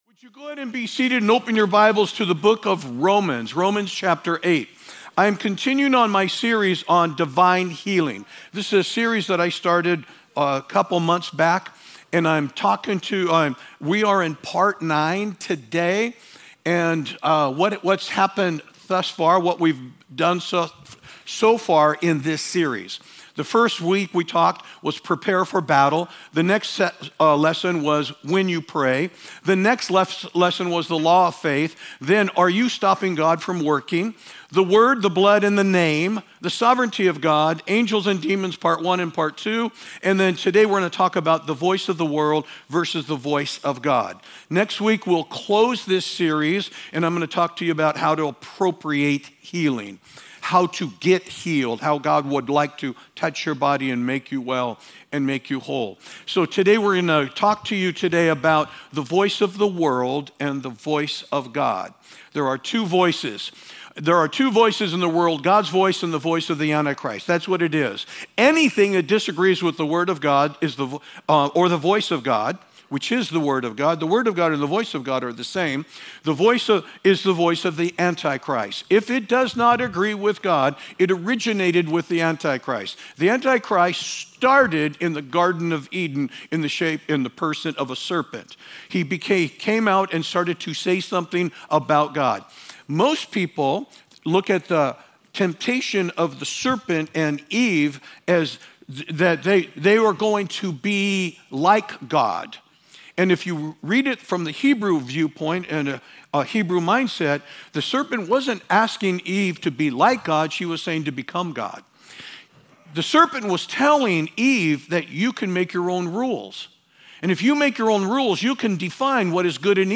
Sermons | Church of Grace